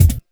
41 BD 01  -L.wav